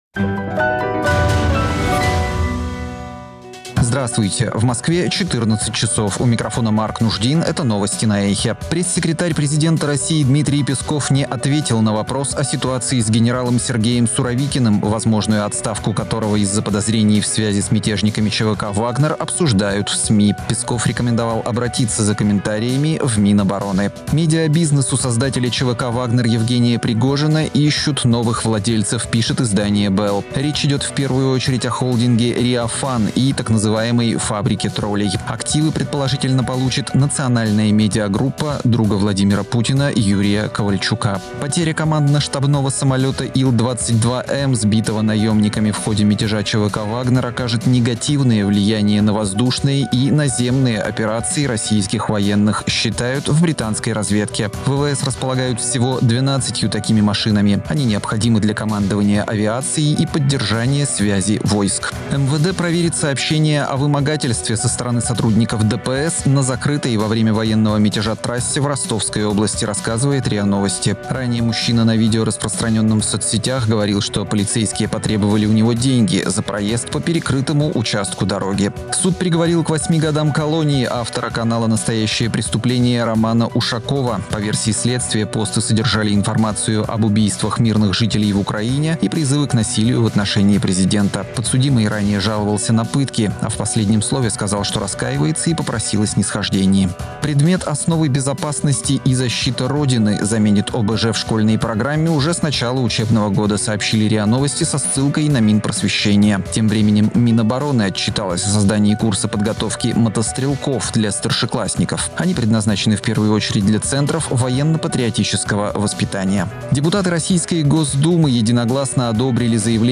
Слушайте свежий выпуск новостей «Эха»
Новости 14:00